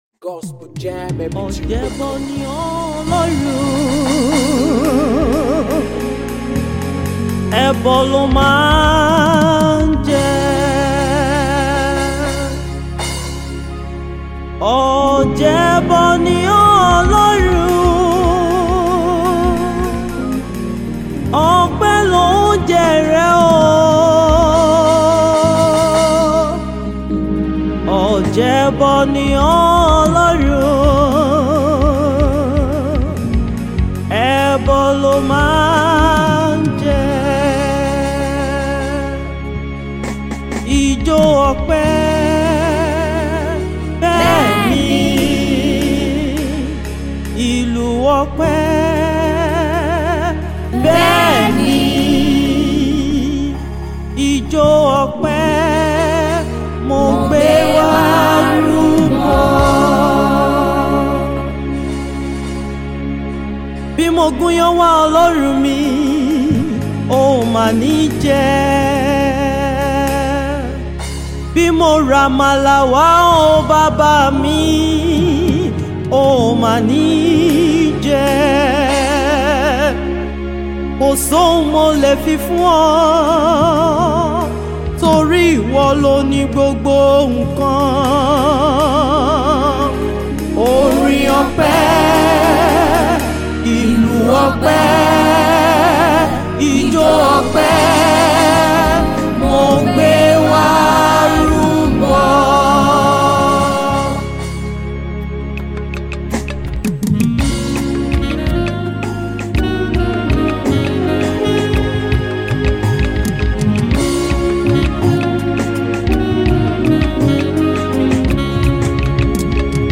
African praise Afro beat music